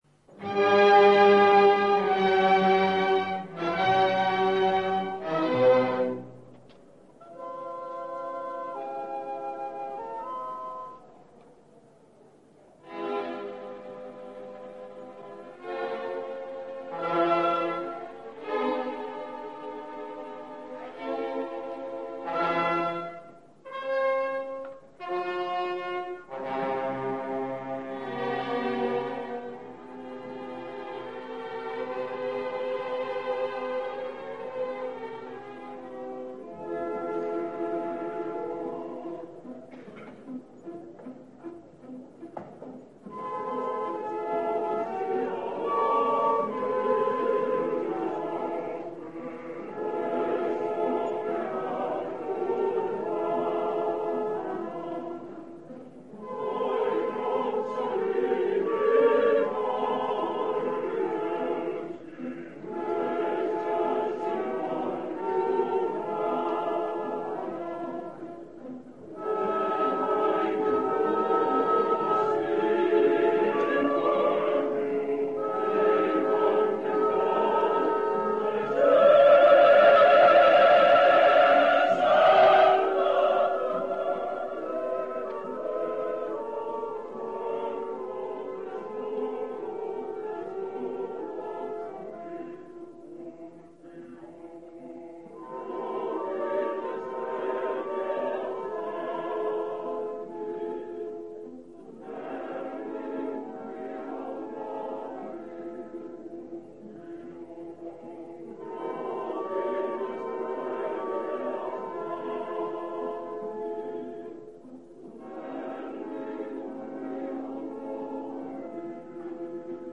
registrazione dal vivo.
Coro, Beatrice, Agnese, Orombello